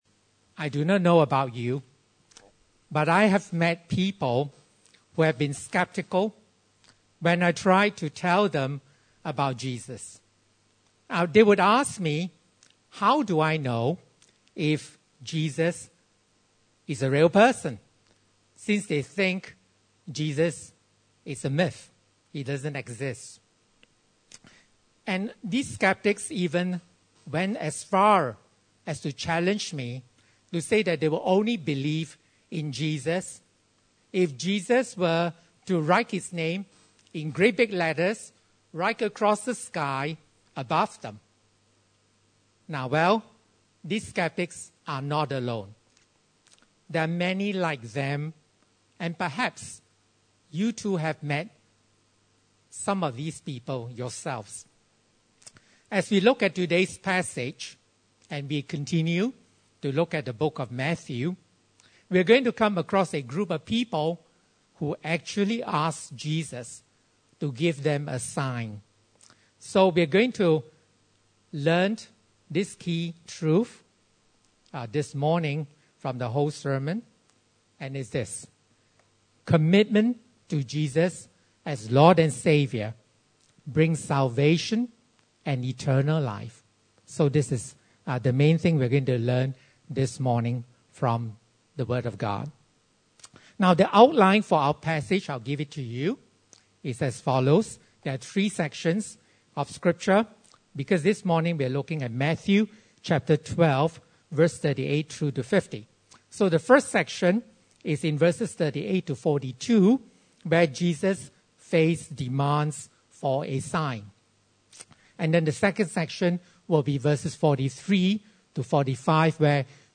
Passage: Matthew 12:38-50 Service Type: Sunday Service